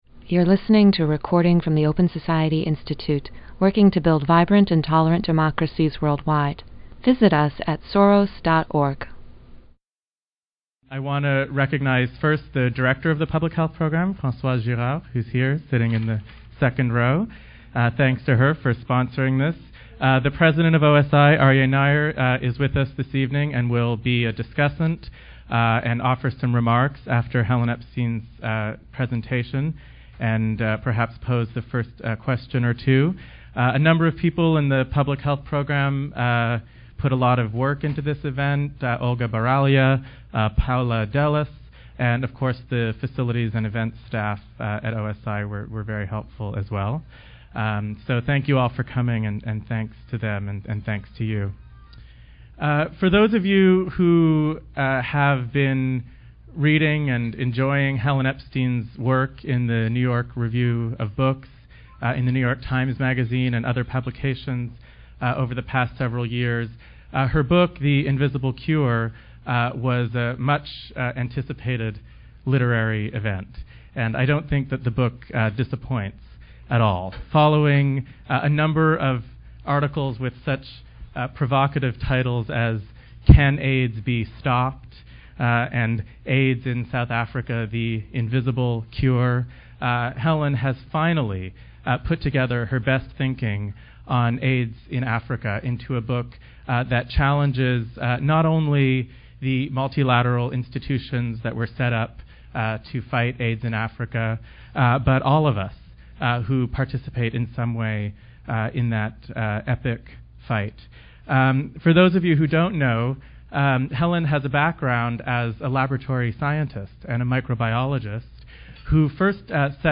OSI convened a panel of human rights experts and activists to discuss the recent upheaval in Pakistan.